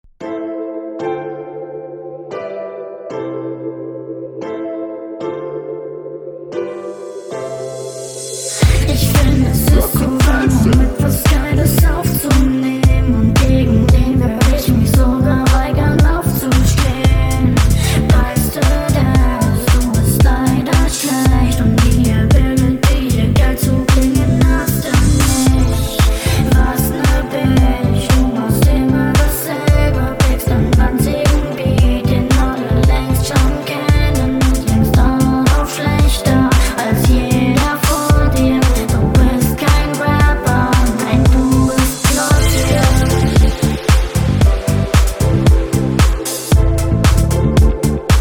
Flowst solide auf dem Beat.